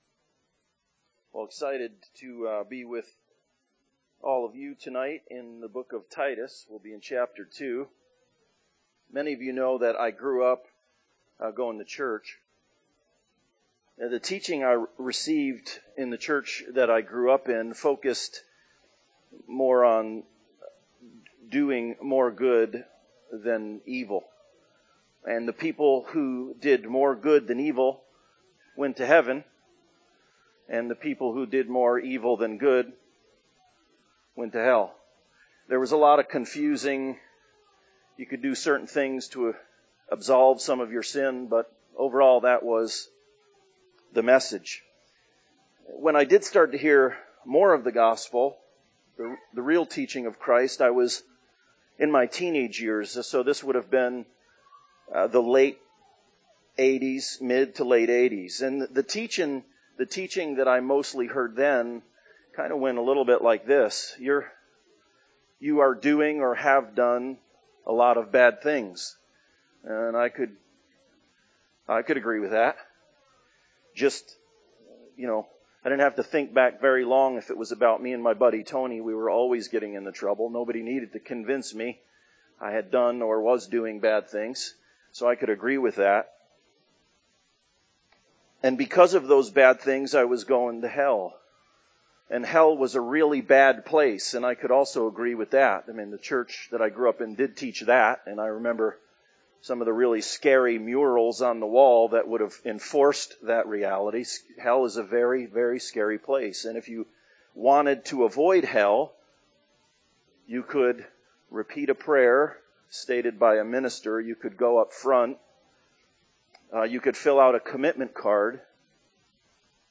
Grace Toward Glory Passage: Titus 2:1-10 Service Type: Sunday Service « Together